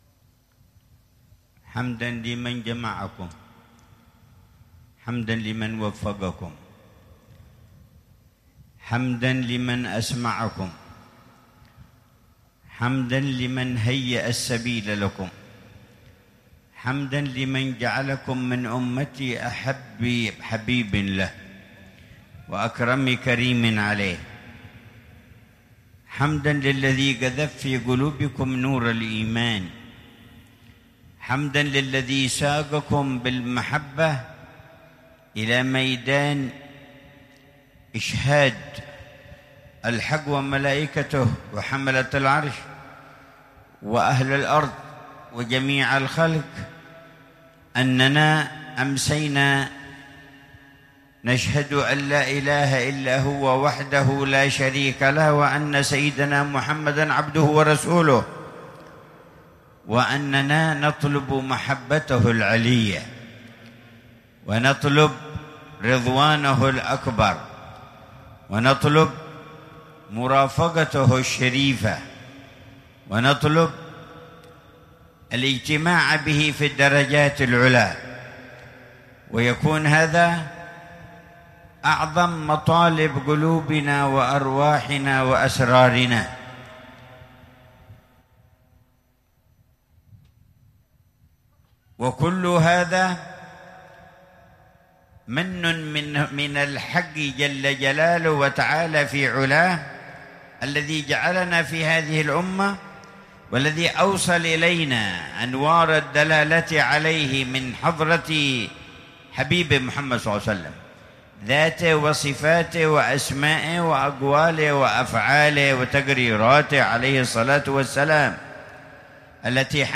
كلمة الاحتفال العام في ذكرى المولد النبوي الشريف - ساحة دار المصطفى بتريم 1446هـ
محاضرة العلامة الحبيب عمر بن محمد بن حفيظ في الحفل السنوي ضمن احتفالات المسلمين بذكرى المولد النبوي الشريف في الساحة الشرقية بدار المصطفى بتريم للدراسات الإسلامية، ليلة الثلاثاء 28 ربيع الأول 1446ه